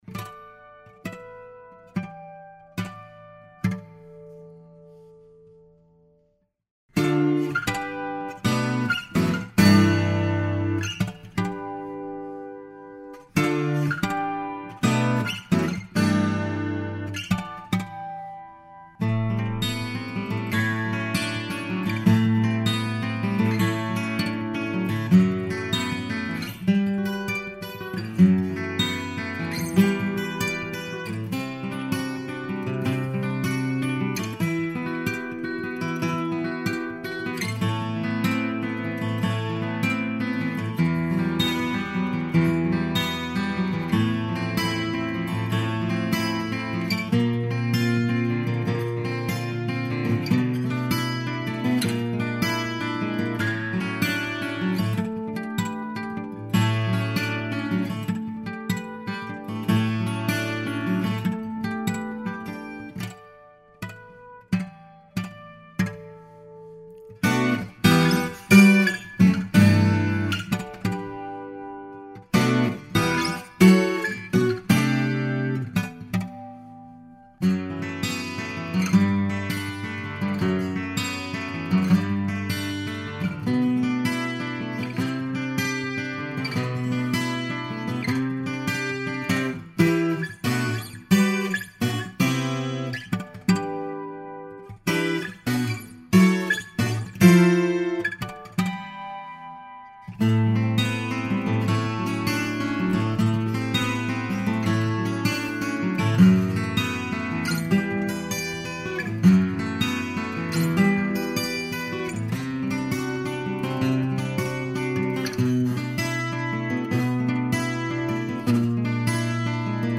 Older pieces made with older audio gear…